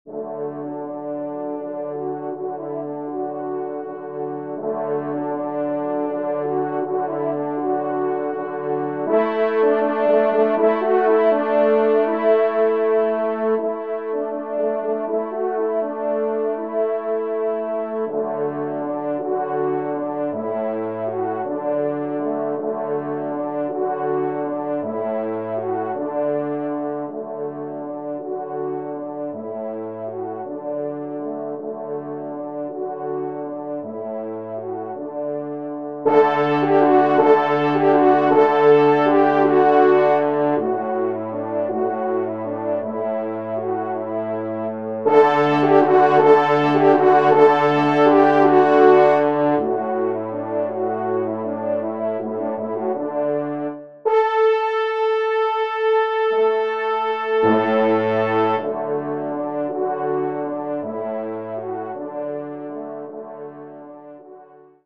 Genre :  Divertissement pour Trompes ou Cors en Ré
4e Trompe